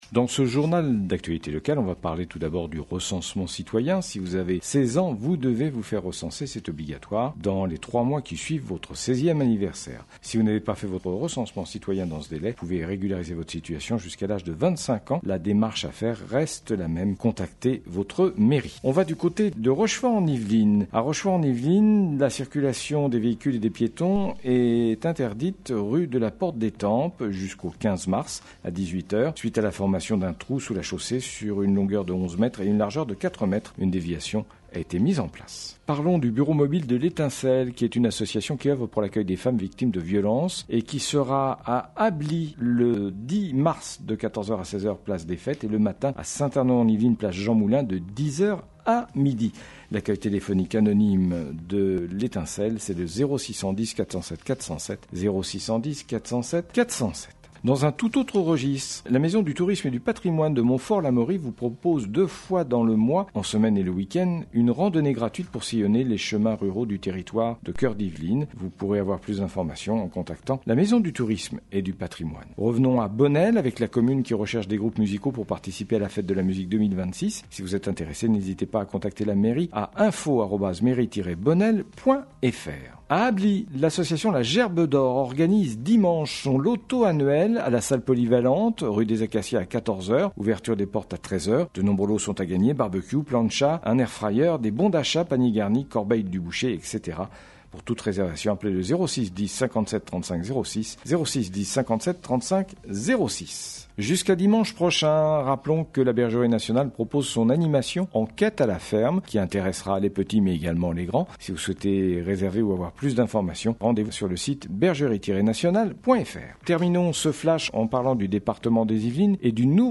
L'information locale Le journal local du vendredi 6 mars 2026